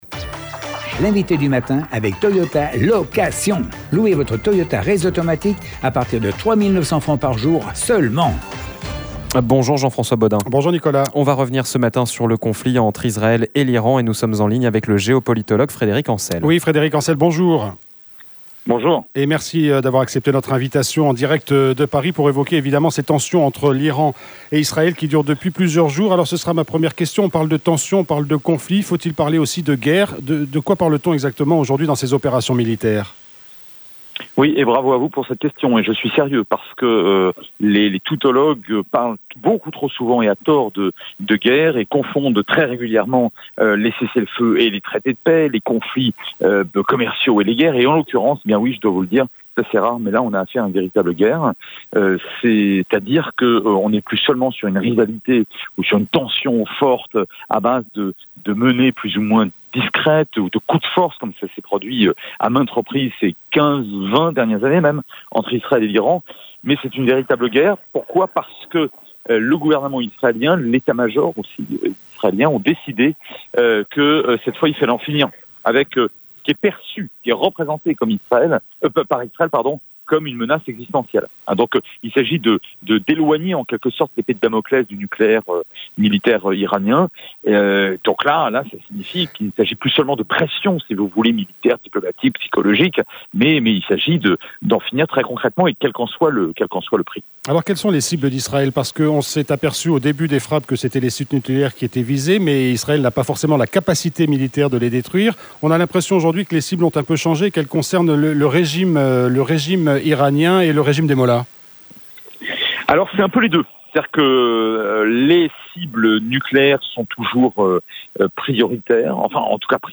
Nous avons parlé du conflit au Proche-Orient et notamment des tensions militaires entre Israël et l’Iran avec notre Invité du Matin. Nous étions en effet en direct avec le géo-politologue Frédéric Encel, spécialiste du Proche-Orient. Le conflit entre Israël et l’Iran va-t-il s’envenimer ?